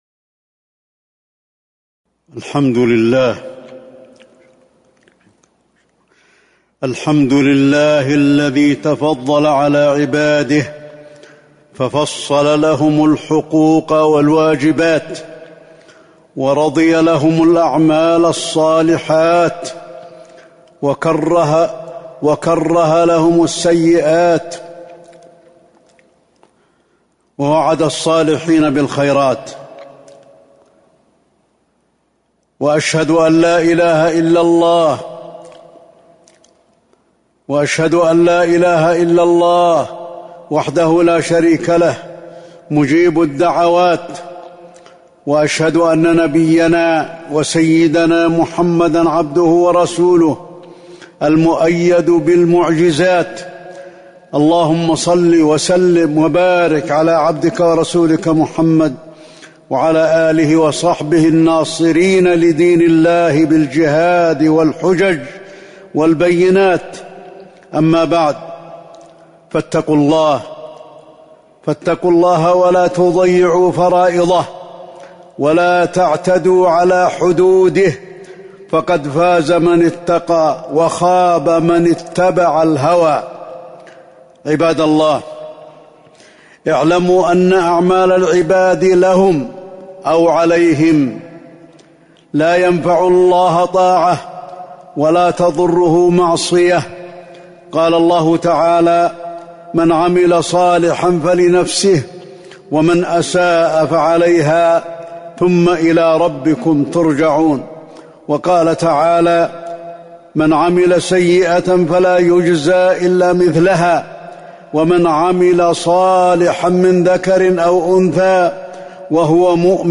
تاريخ النشر ٢٠ جمادى الأولى ١٤٤٣ هـ المكان: المسجد النبوي الشيخ: فضيلة الشيخ د. علي بن عبدالرحمن الحذيفي فضيلة الشيخ د. علي بن عبدالرحمن الحذيفي بر الوالدين